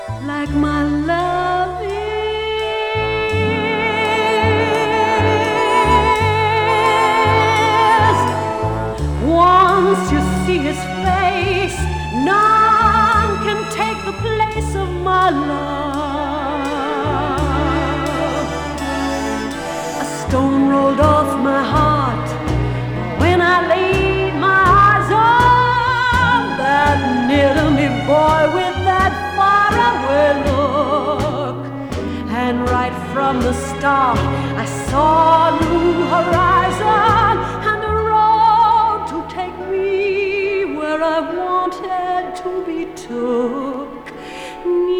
民族音楽や世界の楽器、最新の電子楽器も、貪欲にぶち込んでミックス。
Pop, Stage&Screen, Experimemtal　USA　12inchレコード　33rpm　Stereo